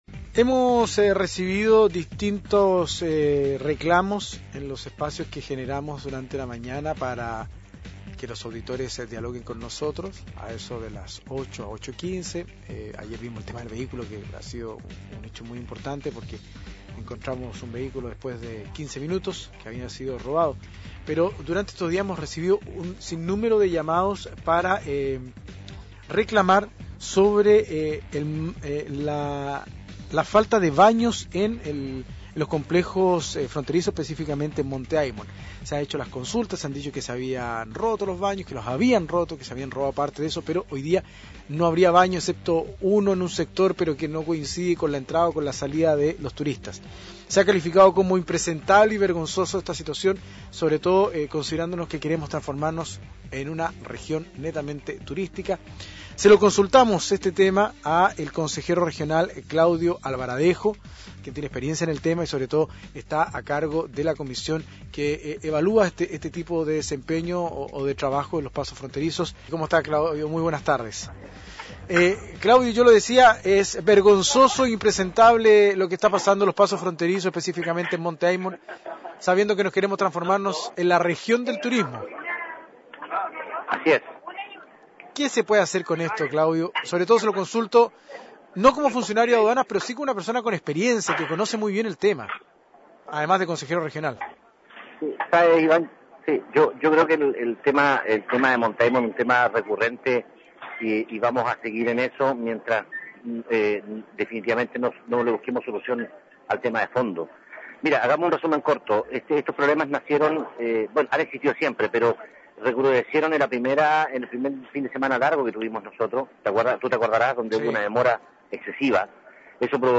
Entrevistas de Pingüino Radio - Diario El Pingüino - Punta Arenas, Chile
Claudio Alvaradejo, consejero regional